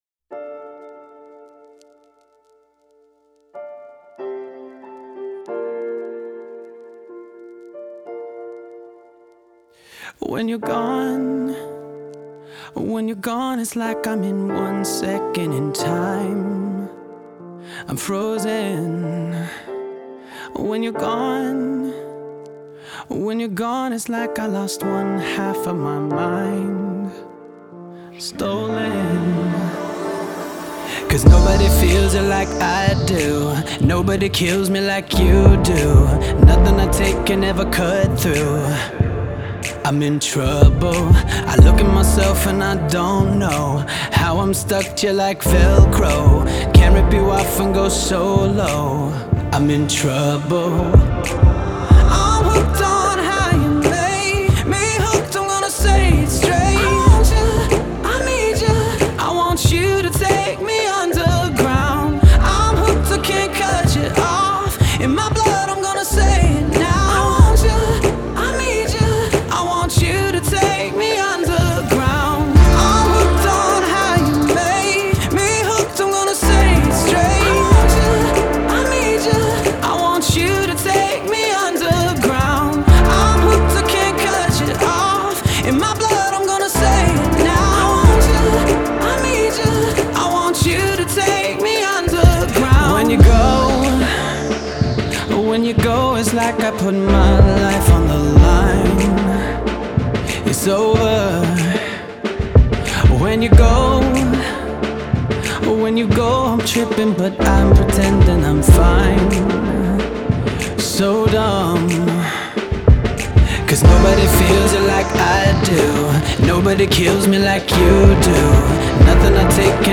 Genre: Pop, Dance